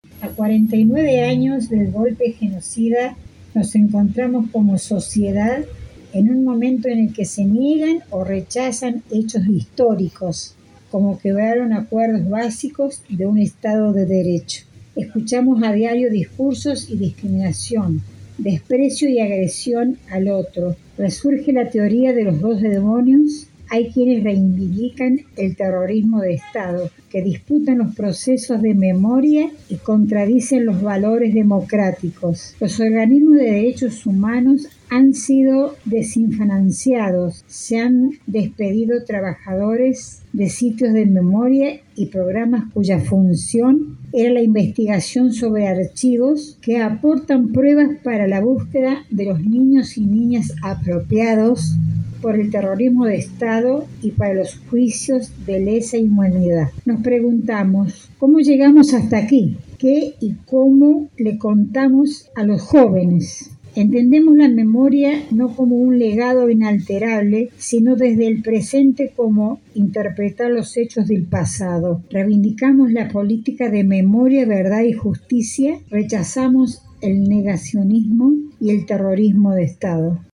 El encuentro tuvo lugar en la tarde de este lunes, 24 de marzo, en plaza San Martín de Villa Mercedes.